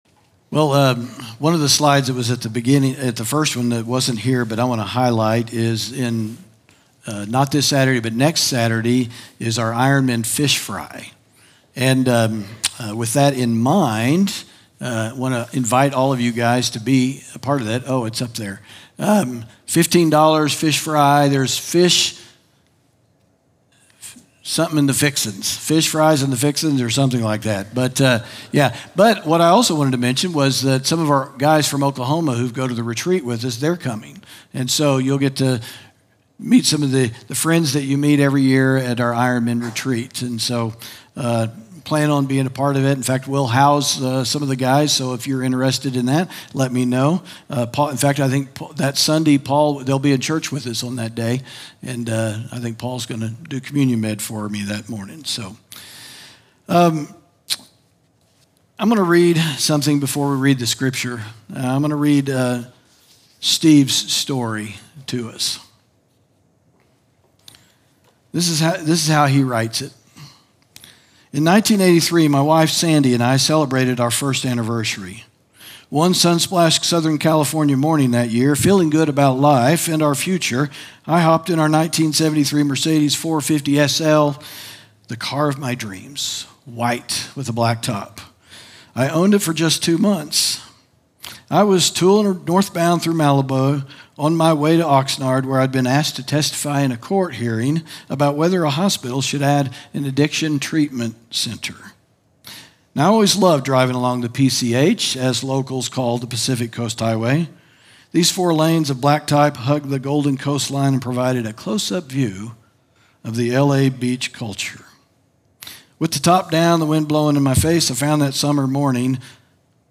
sermon audio 0713.mp3